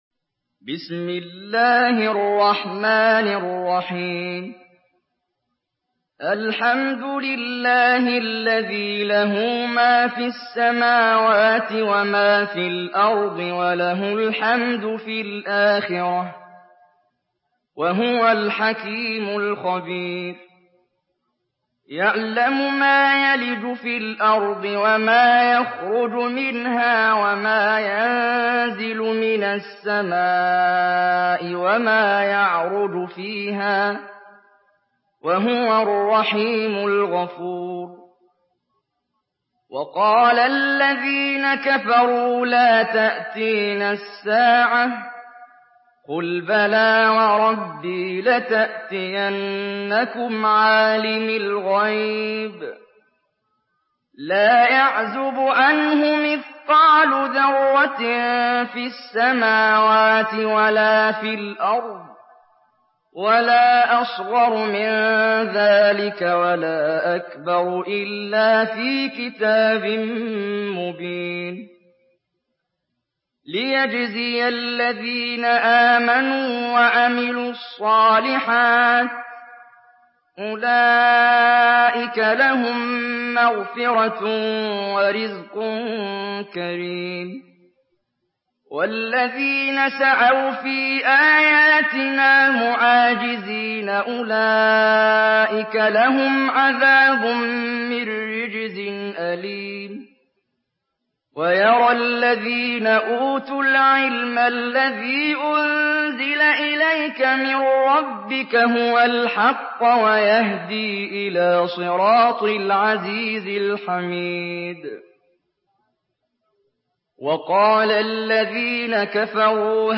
Surah সাবা MP3 by Muhammad Jibreel in Hafs An Asim narration.
Murattal Hafs An Asim